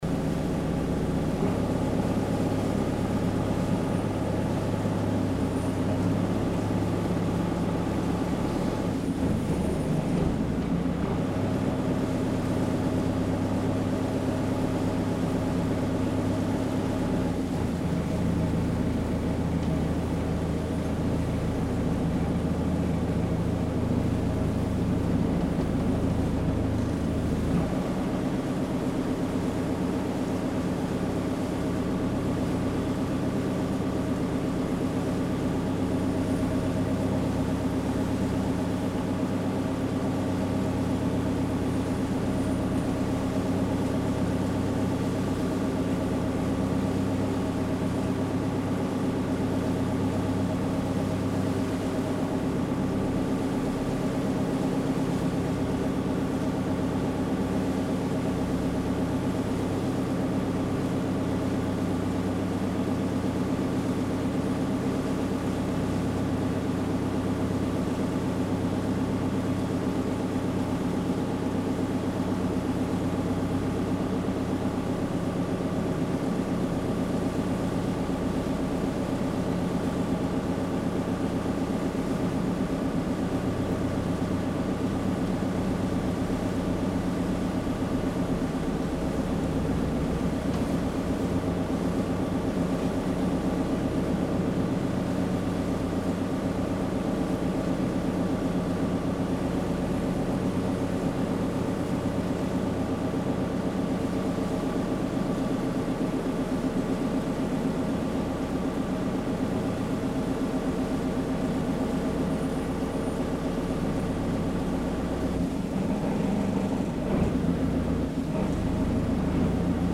因航程極短，只能收錄不足三分鐘的錄音，能夠聽到渡輪行駛中引擎和靠岸的聲音。
As the shortest ferry route in Hong Kong, the journey takes only four minutes. Due to the extremely short trip, the recording captures less than three minutes, featuring sounds of the ferry’s engine during the voyage and the docking process.
交通 Transport
Tascam Portacapture X8 w/ Clippy EM272
三腳架 Tripod